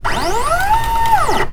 turret.wav